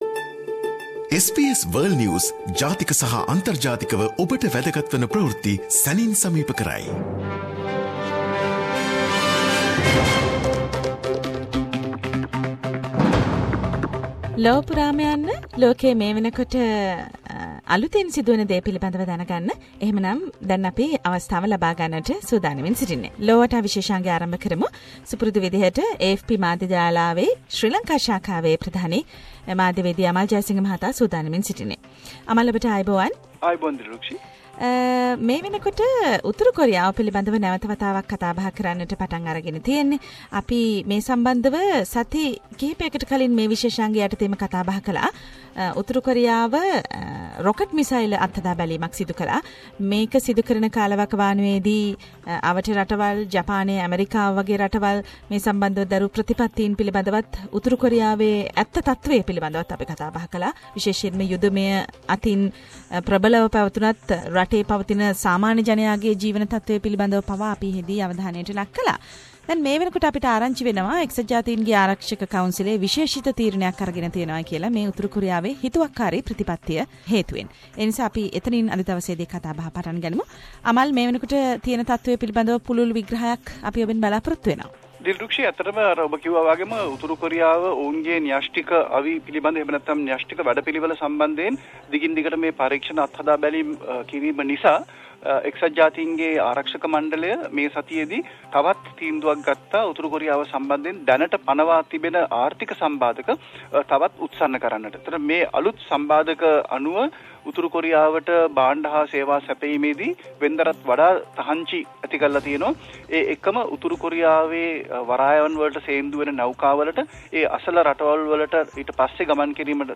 SBS Sinhala Around the World - Weekly World News highlights